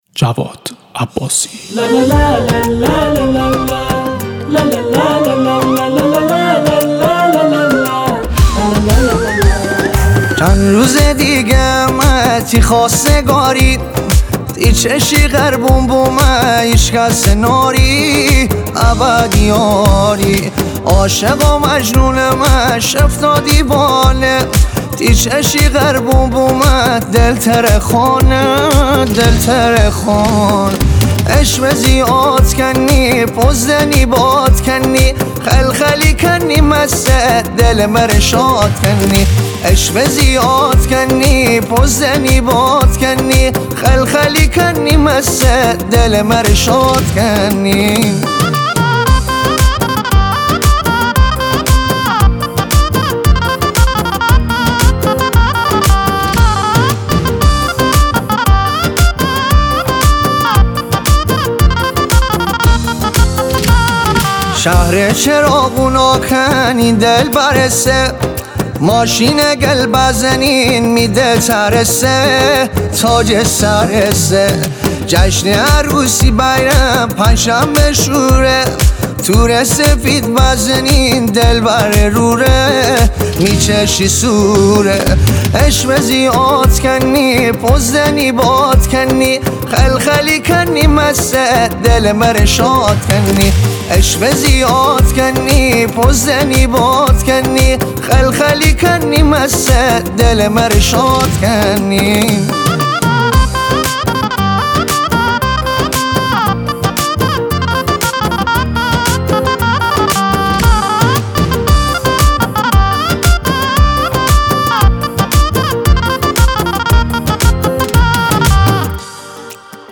محلی